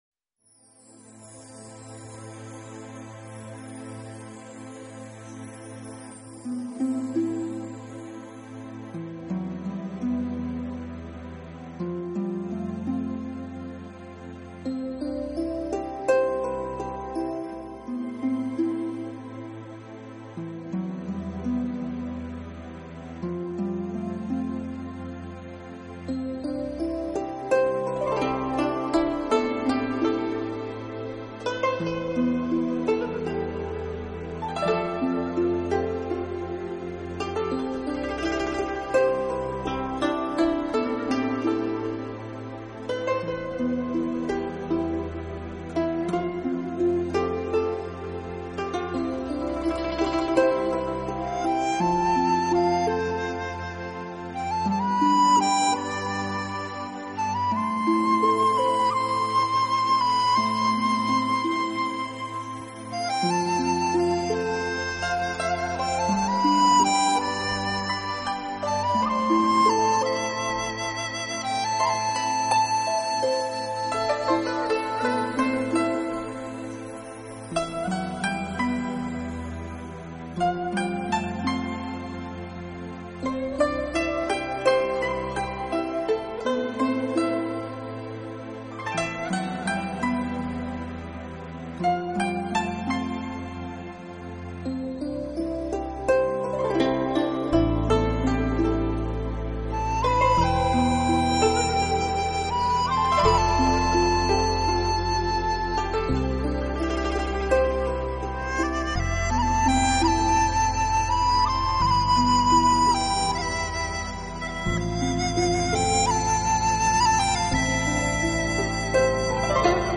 音乐风格：New Age
其中充斥着各种大自然音效，也正因为这些大量的自然音效，使的